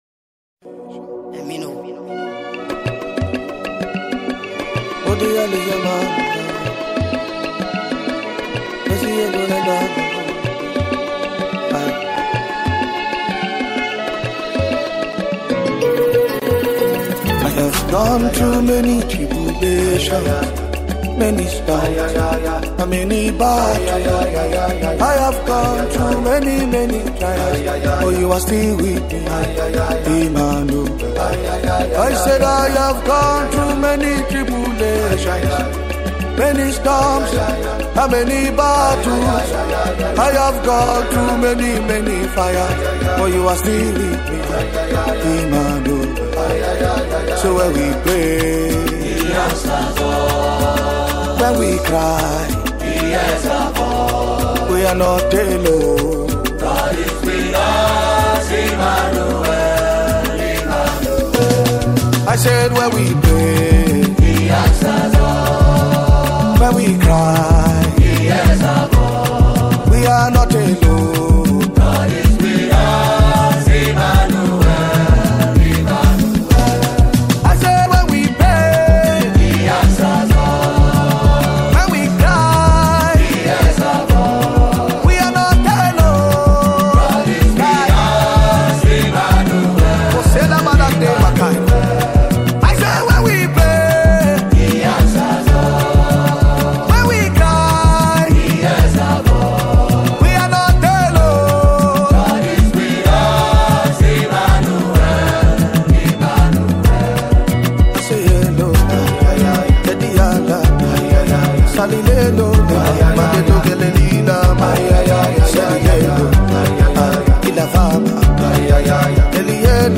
PRAYER SOUND
Chant Music
Worship